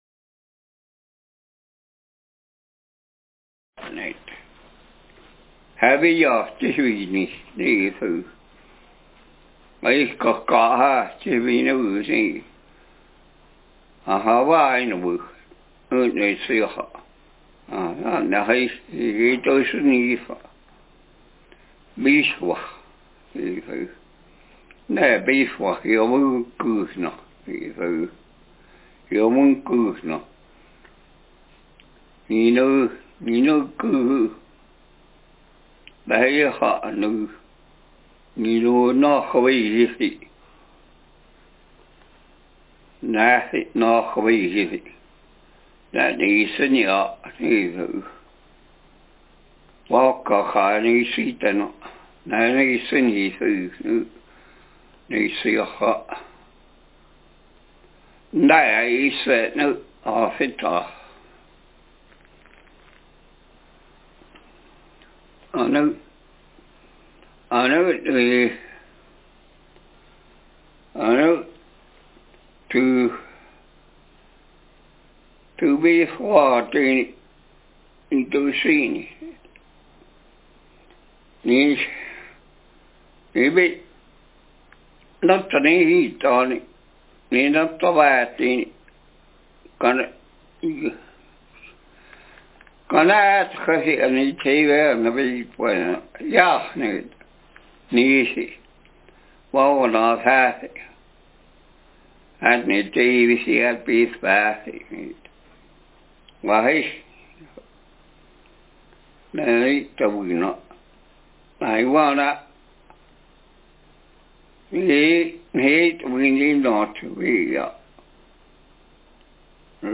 Speaker sex m Text genre personal narrative